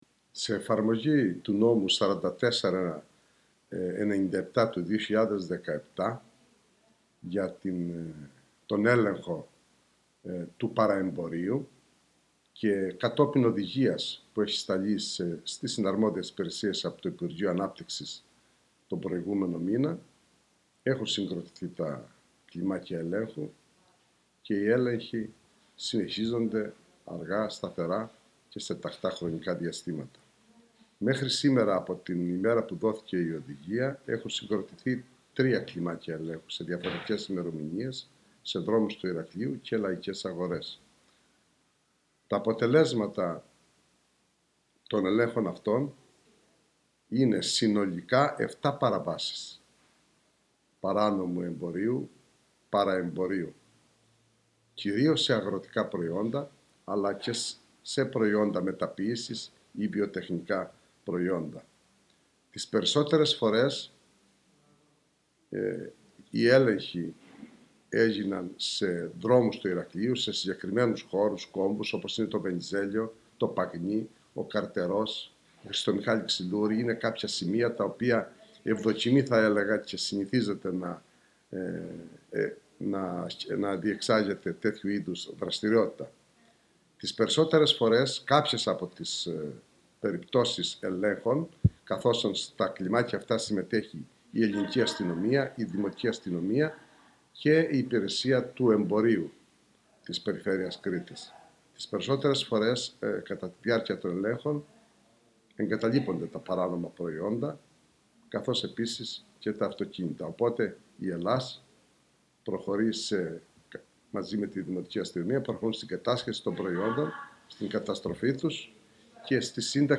Ακούστε εδώ τις δηλώσεις του Πολιτικού Προϊστάμενου Δημοτικής Αστυνομίας Μαρίνου Παττακού: